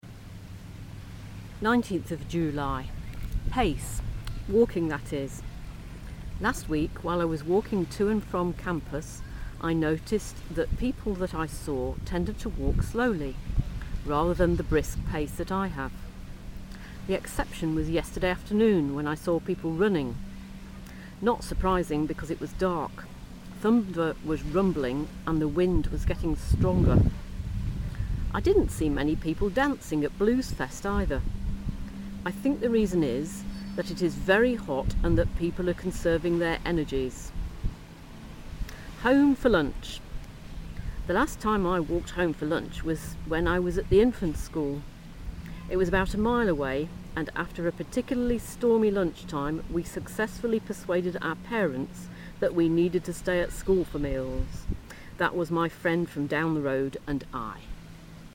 Pace, Home for lunch (outside broadcast)